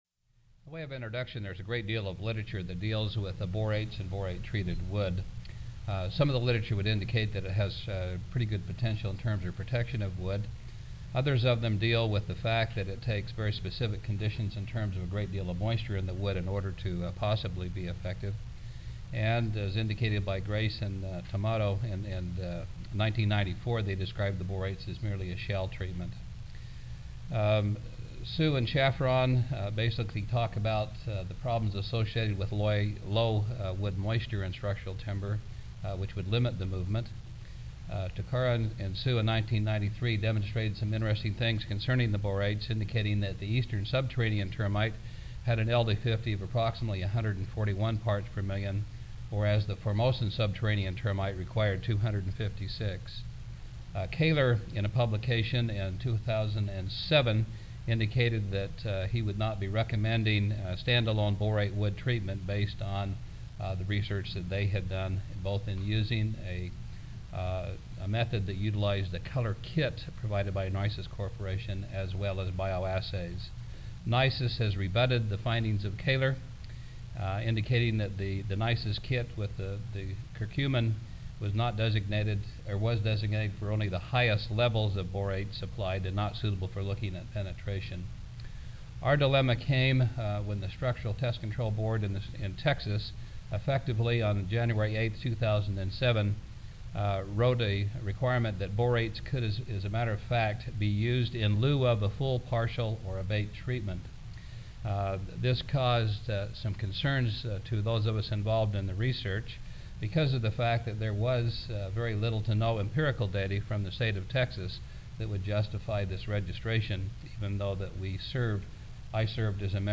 Ten-Minute Papers, Section Fb. Urban Entomology
Recorded presentation